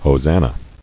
[ho·san·na]